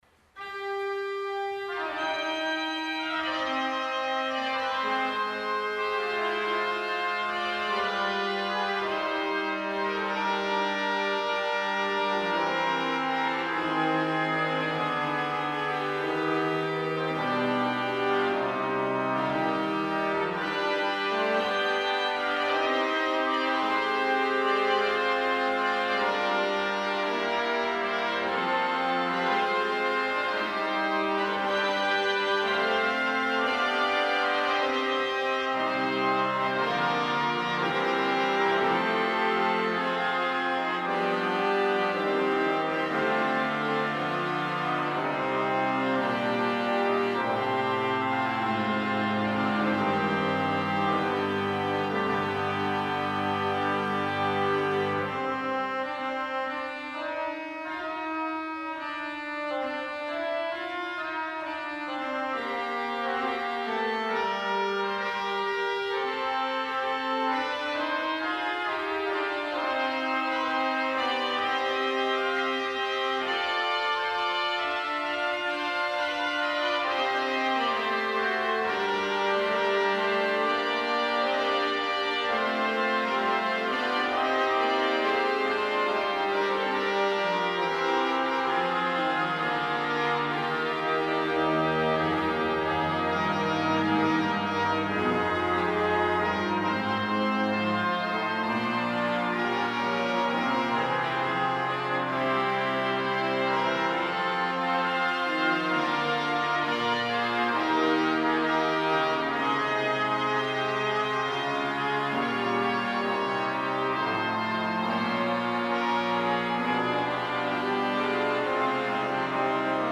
- Les concerts passés à l'église de Domgermain - Entrée libre -
03/10/20: Récital d'Orgue par Francis Chapelet.